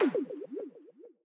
ORG Water Hit FX.wav